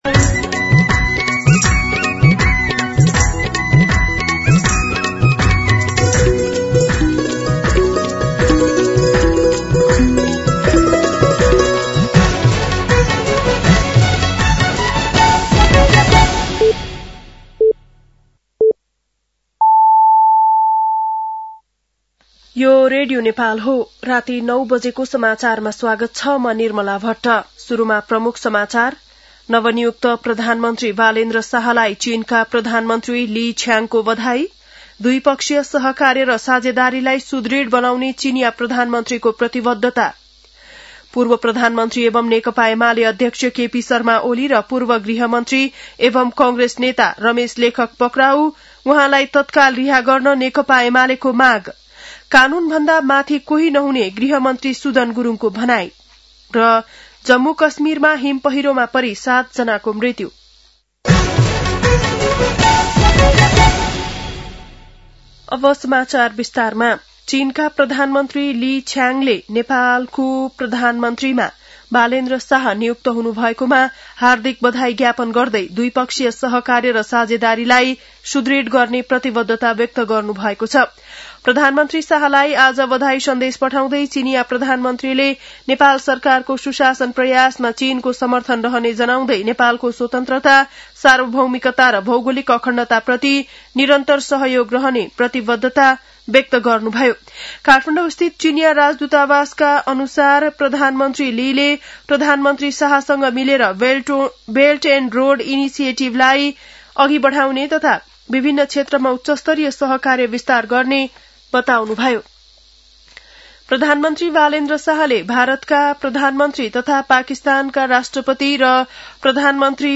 बेलुकी ९ बजेको नेपाली समाचार : १४ चैत , २०८२
9-pm-nepali-news-1-3.mp3